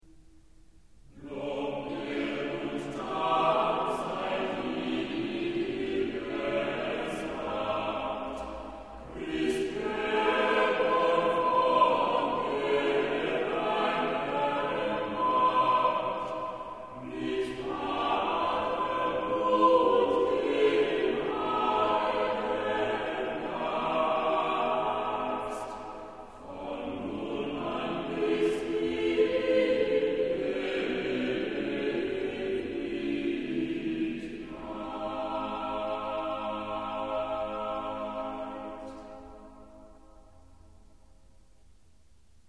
Choral: Christum wir sollen loben schon